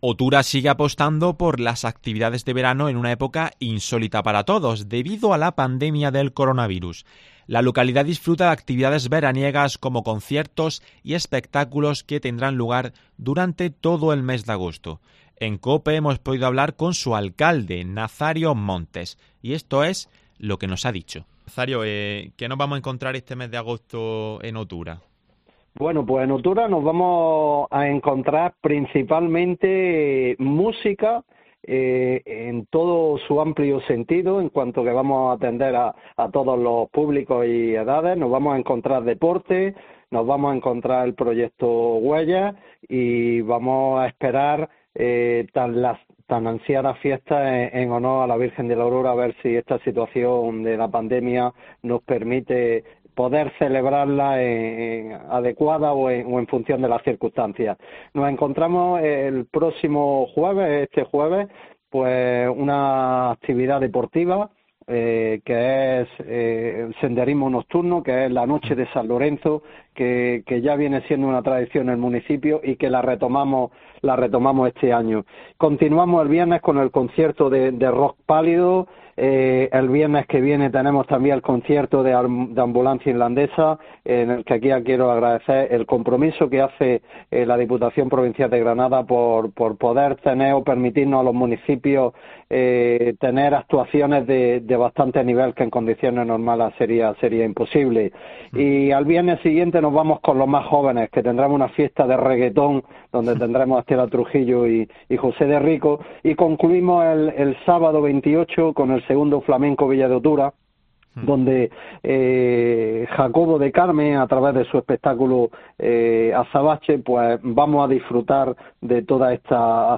En COPE, hemos hablado con su alcalde, Nazario Montes y esto es lo que nos ha dicho.